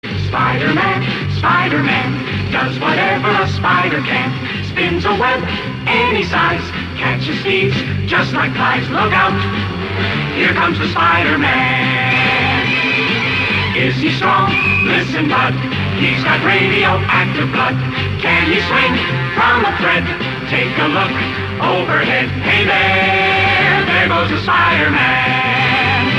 • Качество: 224, Stereo